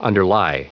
Prononciation du mot underlie en anglais (fichier audio)
Prononciation du mot : underlie